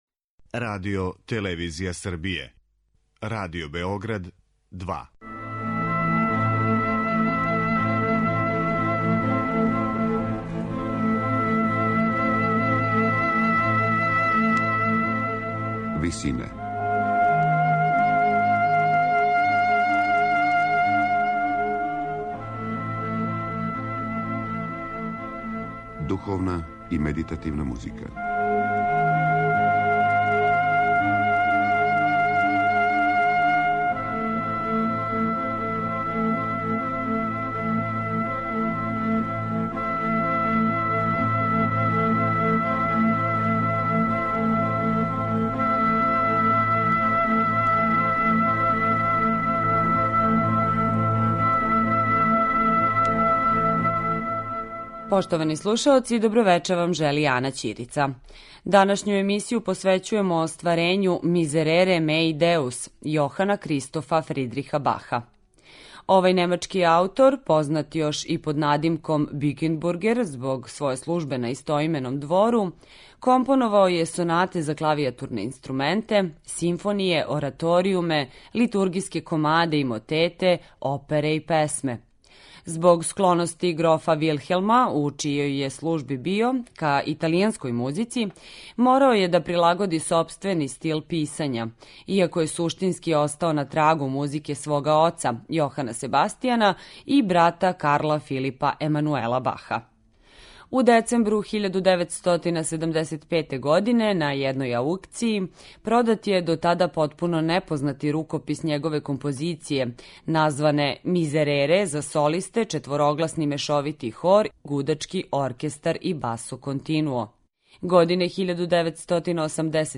Прву овонедељну емисију посвећујемо остварењу које је за солисте, хор и гудаче, а на стихове Псалама 50 и 51, компоновао Јохан Кристоф Фридрих Бах.